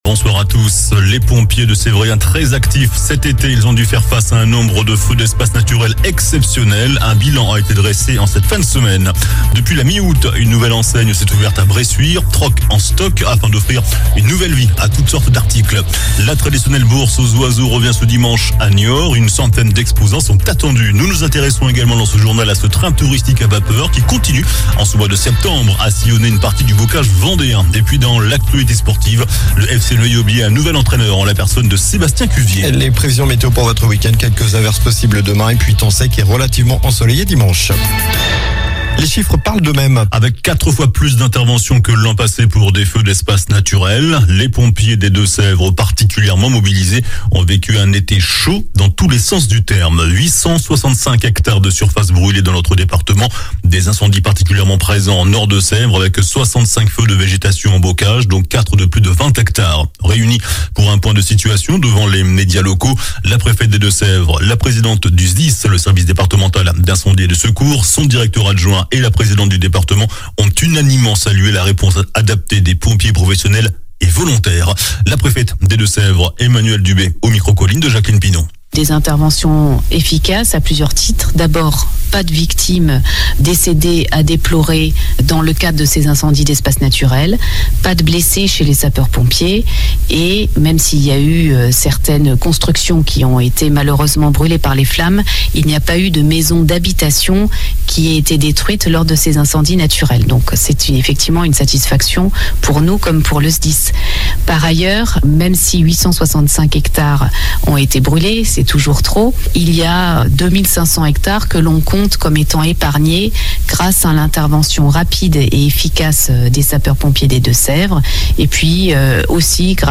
JOURNAL DU VENDREDI 02 SEPTEMBRE ( SOIR )